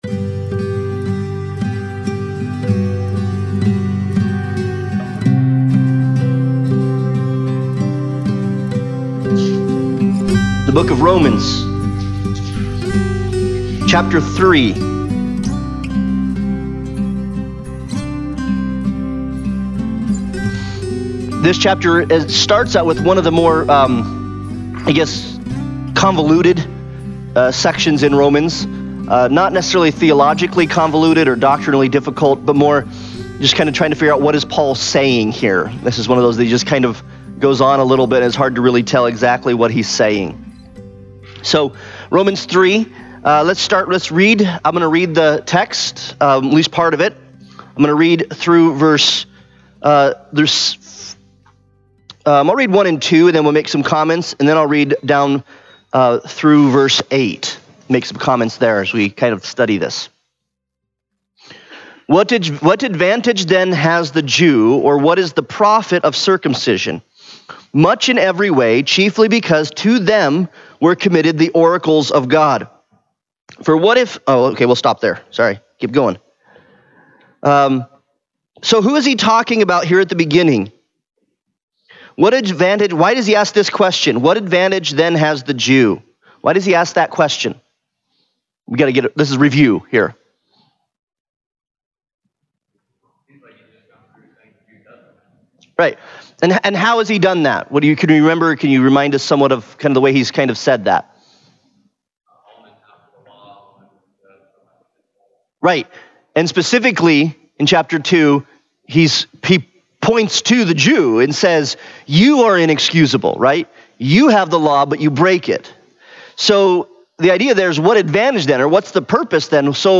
Romans Analysis Passage: Romans 3:1-20 Service Type: Sunday Bible Study « Boast in Christ